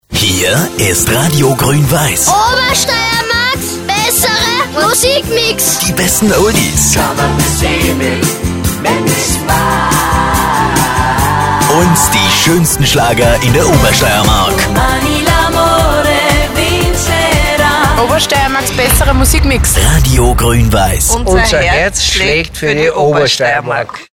deutschsprachiger Sprecher.
Sprechprobe: eLearning (Muttersprache):
german voice over artist